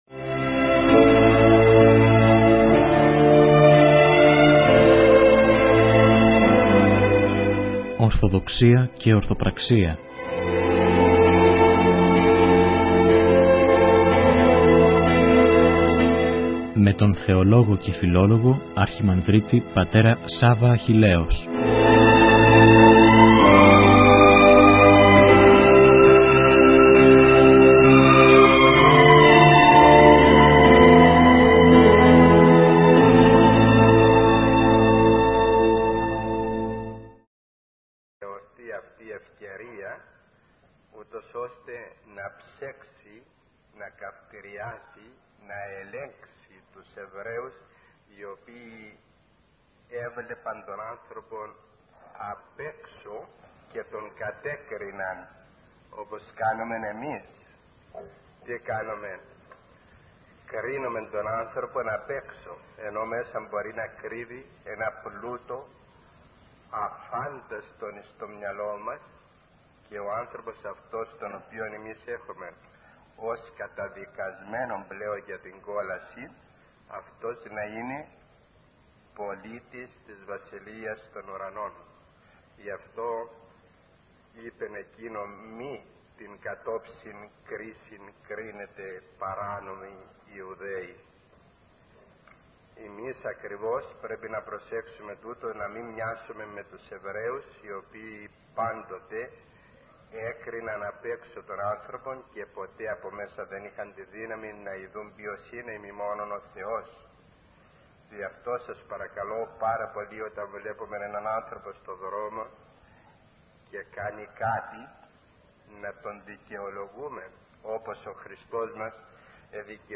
Ἡ ψυχωφέλιμη ὀμιλία